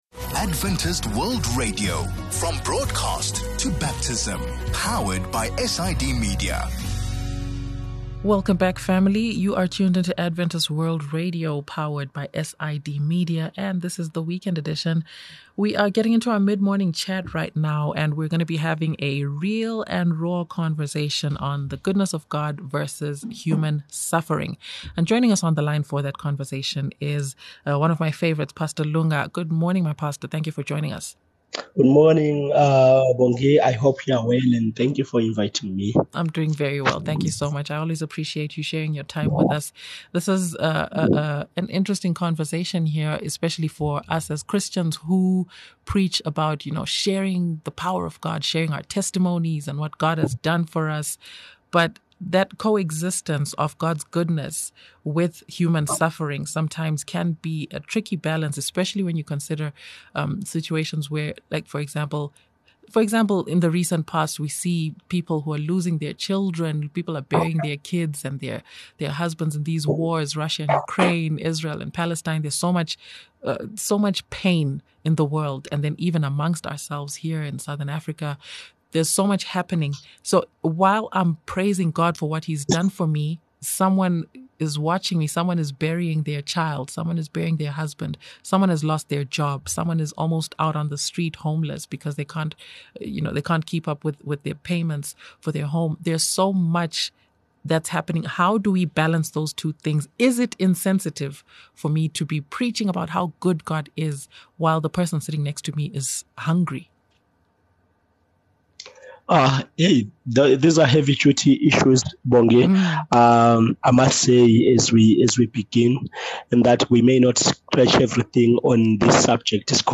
A real and raw conversation on the reality of human suffering in the face of God’s goodness.